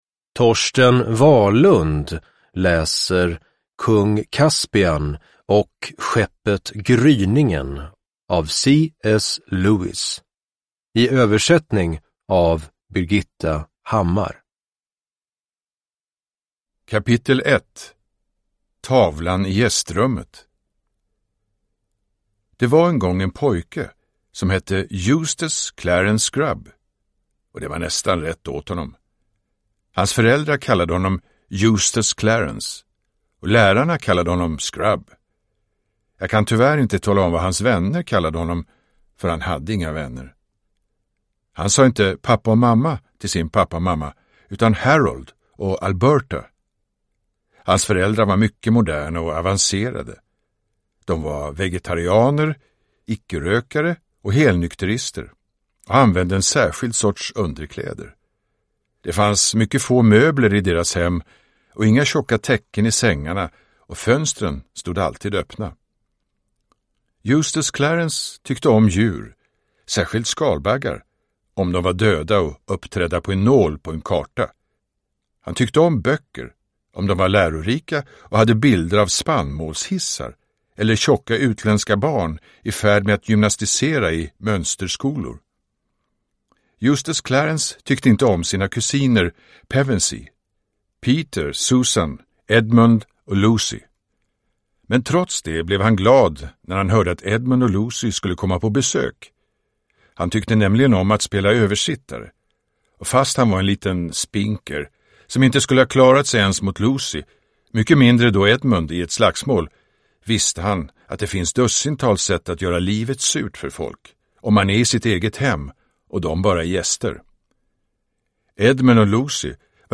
Kung Caspian och skeppet Gryningen – Ljudbok – Laddas ner
Uppläsare: Torsten Wahlund